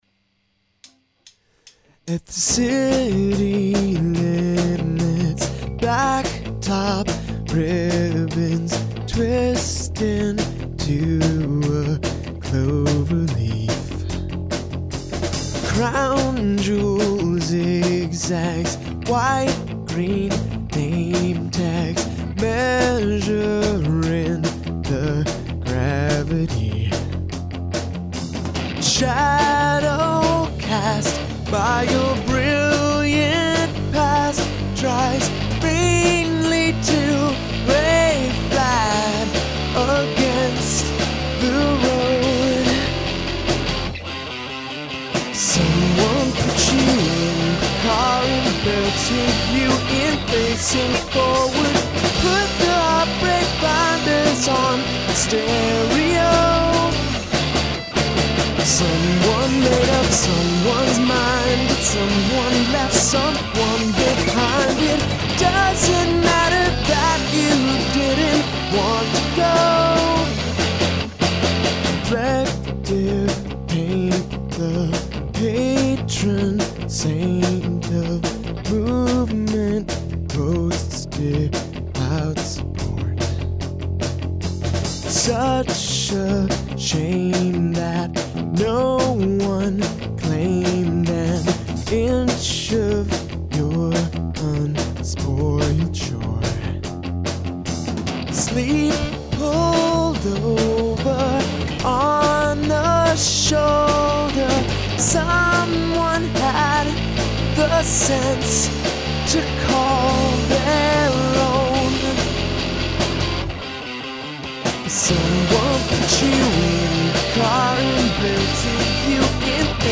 will upload soon. song's in C major.
A B C A B C D C, as standard as they come.
Hence the guitars on the refrain and as much drumming as I could muster.
i think that a studio version by the Passerines will probably end up being the definitive version of this song. it'll have a much better vocal take on it, that's for sure. i apologize for recording vocals for this demo before i could sing the song very well.